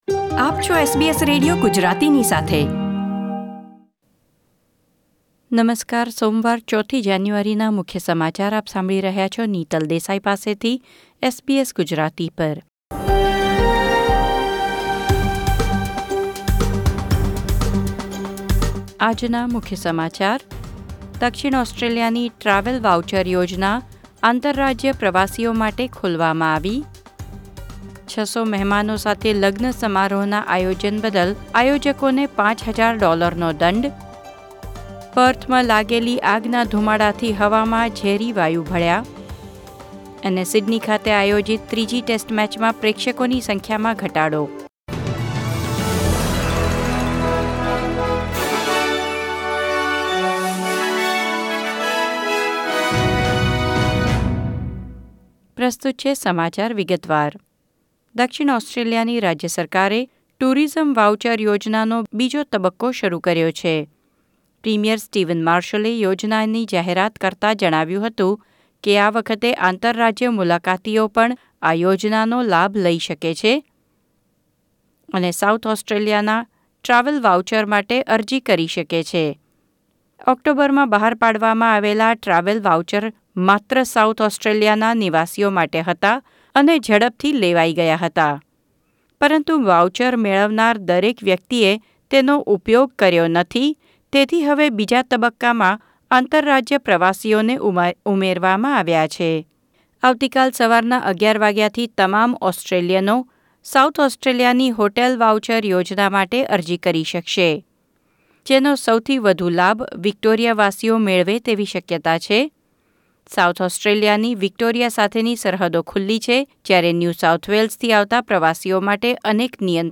SBS Gujarati News Bulletin 4 January 2021